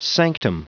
Prononciation du mot sanctum en anglais (fichier audio)
Prononciation du mot : sanctum